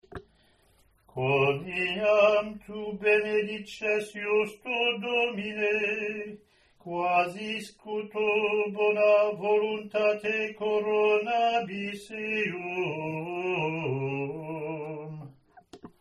Click to hear Communion (Extraordinary Form)Latin antiphonverse 1, verse 2, verse 3, verse 4, verse 5, verse 6,